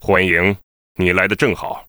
文件 文件历史 文件用途 全域文件用途 Bk_tk_04.ogg （Ogg Vorbis声音文件，长度1.9秒，114 kbps，文件大小：26 KB） 源地址:游戏语音 文件历史 点击某个日期/时间查看对应时刻的文件。